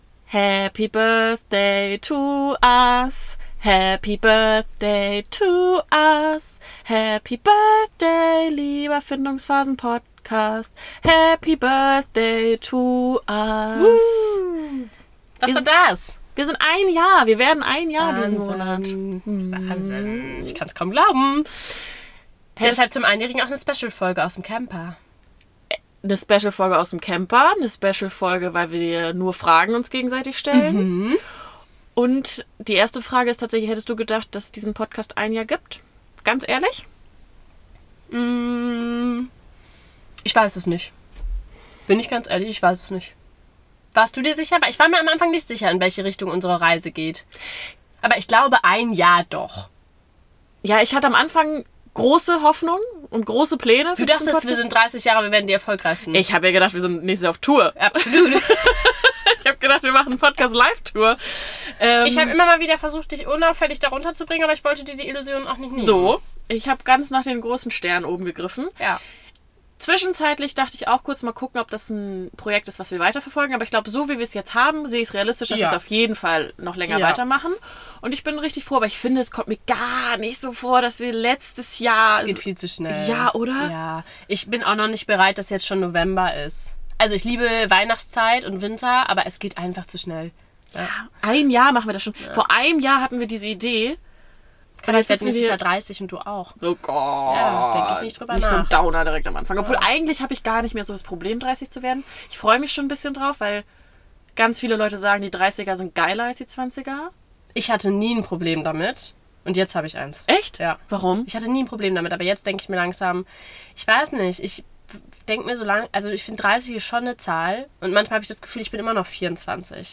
Wir feiern das 1-Jährige in Dänemark in unserem kleinen Camperurlaub. Diese Folge haben wir eure Fragen beantwortet also freut euch auf Geduldsfragen, Ausschlag und schwierige Eigenschaften des anderen.